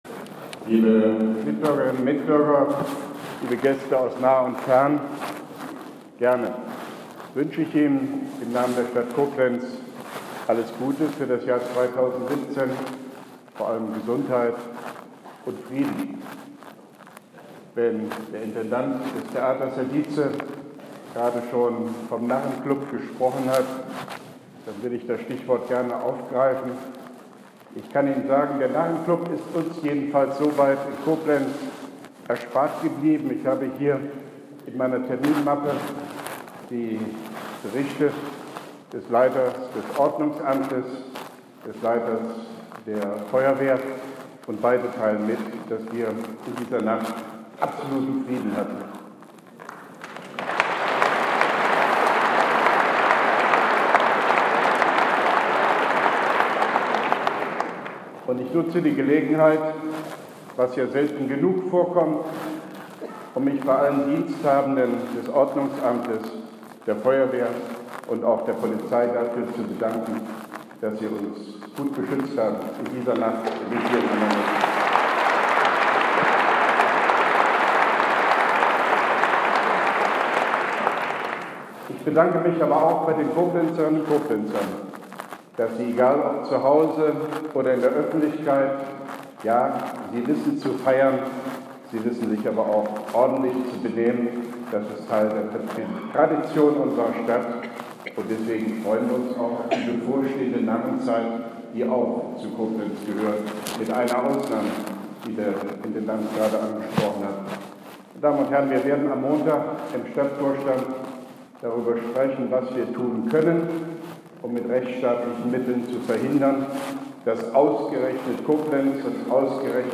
Neujahrsrede.mp3